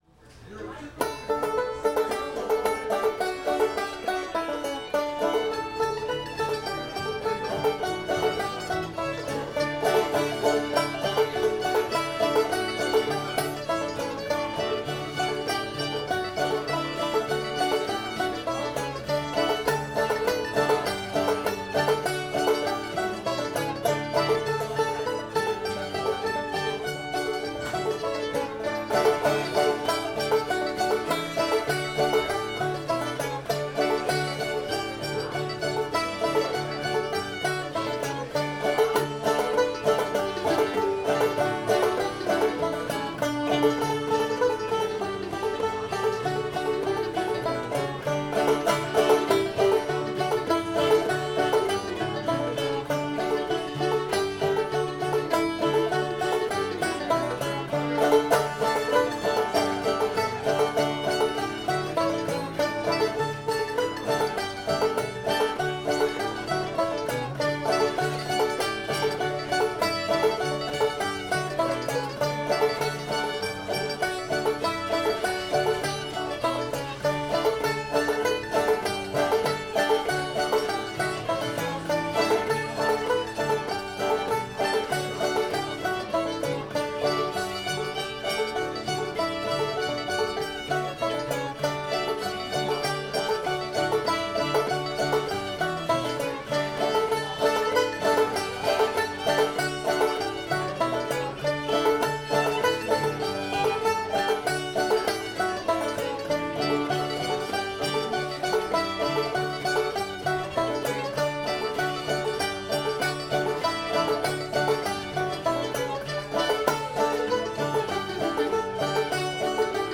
old molly hare [D]